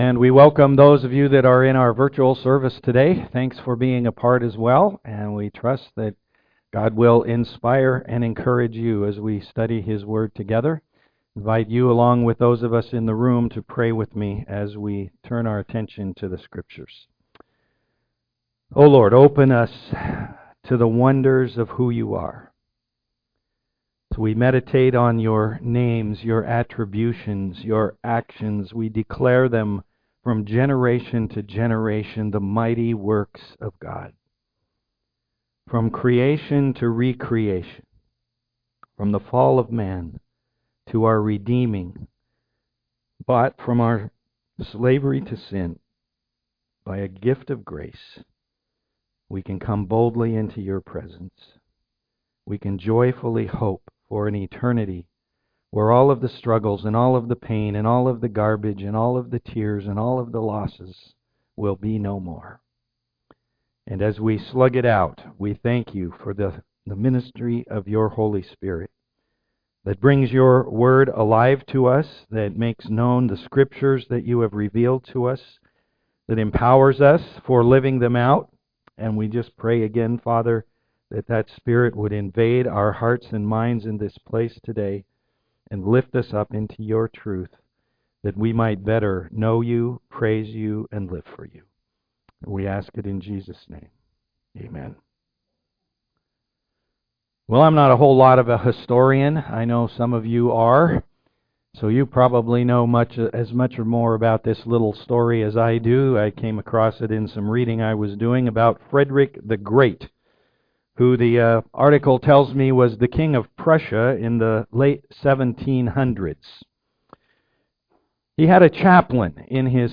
Romans 10:18-11:36 Service Type: am worship God's choosing of Israel has never failed.